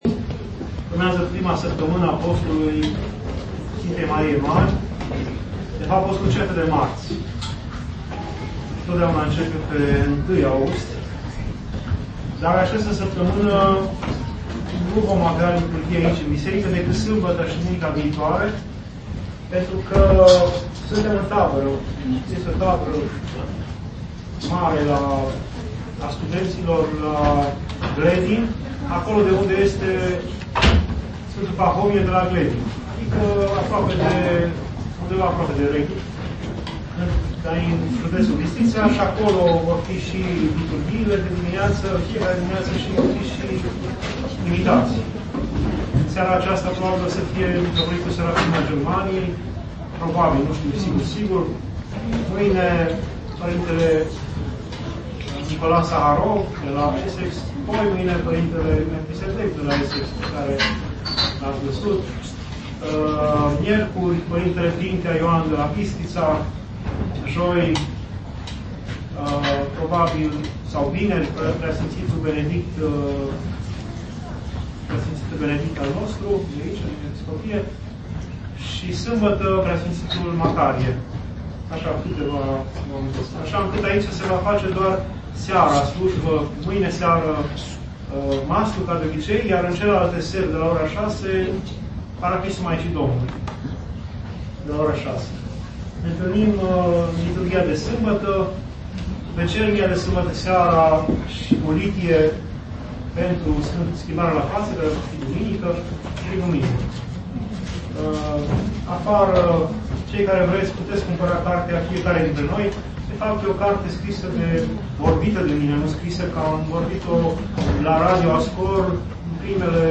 Anunțuri